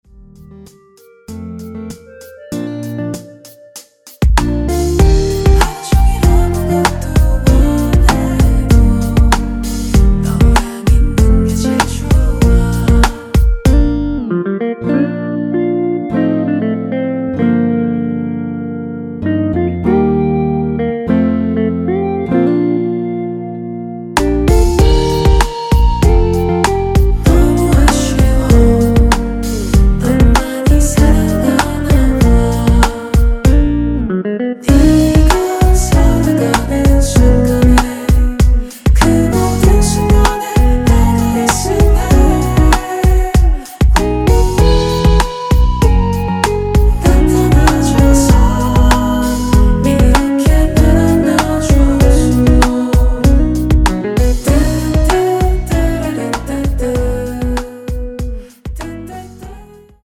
키 Bb 가수
원곡의 보컬 목소리를 MR에 약하게 넣어서 제작한 MR이며